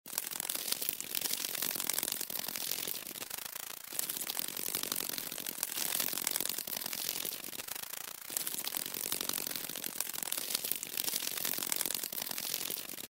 На этой странице собраны разнообразные звуки майского жука: от характерного жужжания до шума крыльев в полете.
Звук крыльев жука